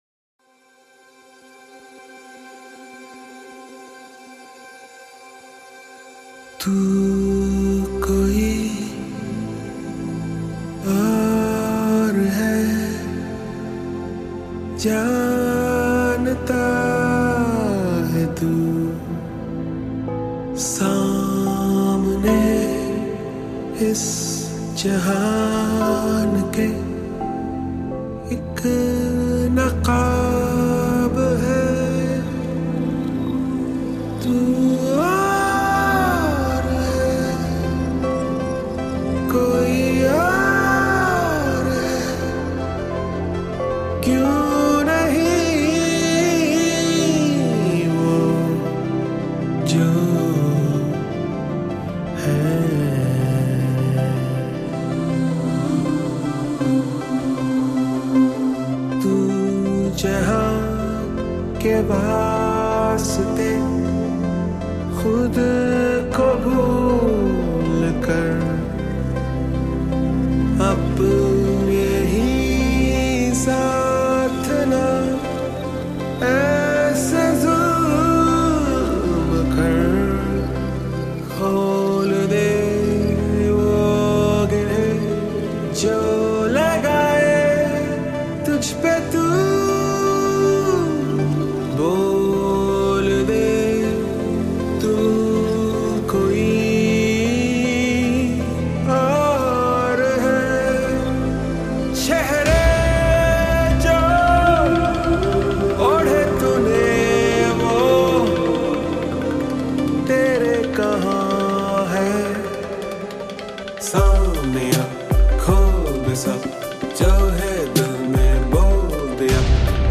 An intense song